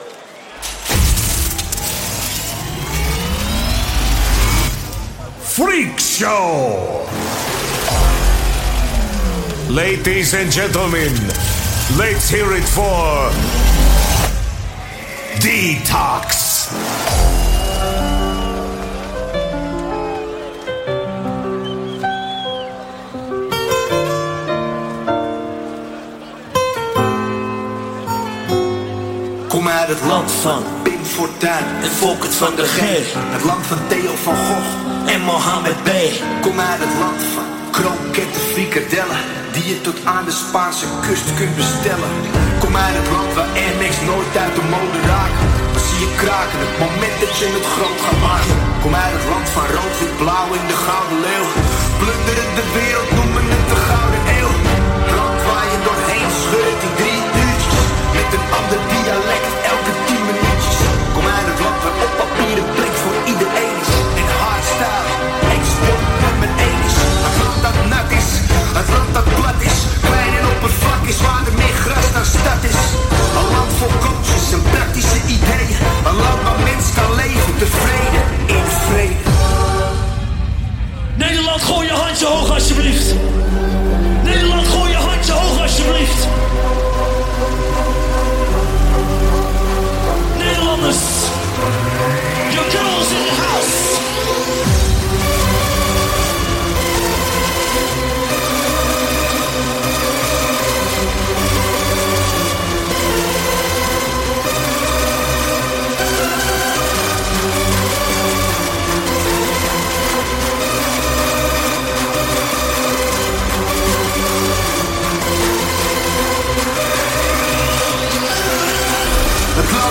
Also find other EDM Livesets, DJ Mixes and
Liveset/DJ mix